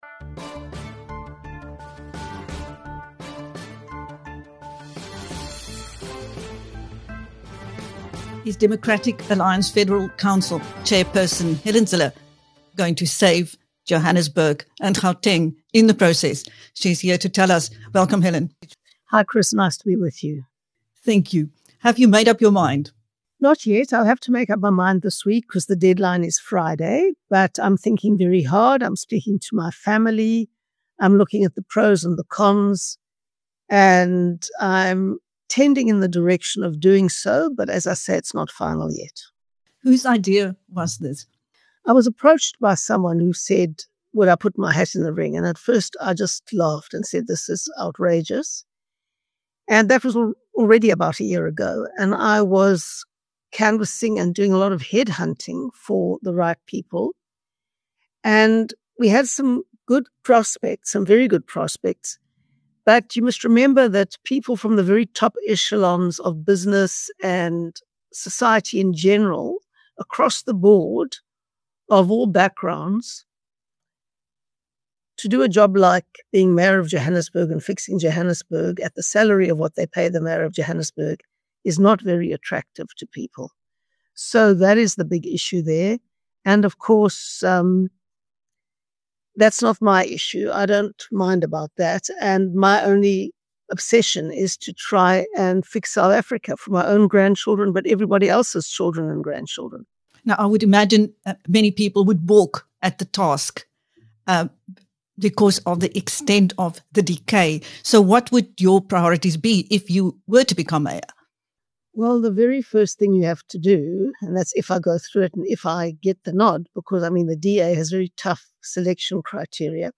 The possibility of Democratic Alliance (DA) Federal Council Chairperson Helen Zille as the next Mayor of Johannesburg has the country talking. In this interview with BizNews, Zille says although she has not yet made up her mind, she is “tending in the direction of doing so”.